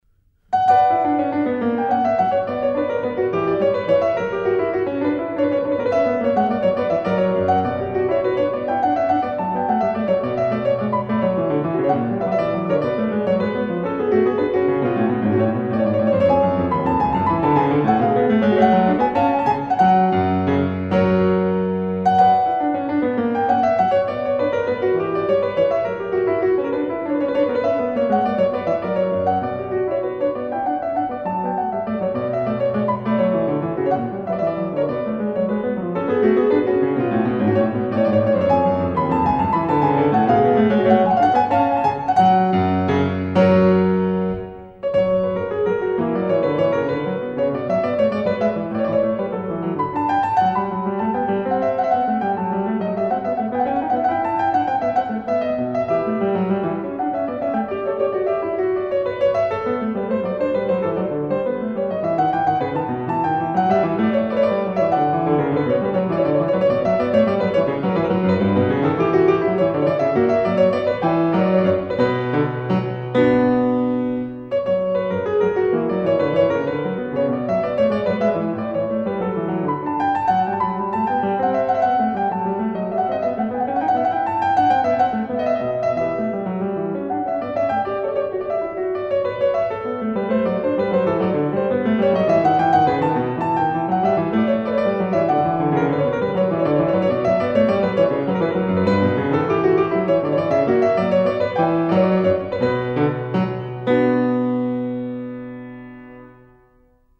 Oui, vous allez me dire, mais qu'est-en-t-il de sa main gauche ?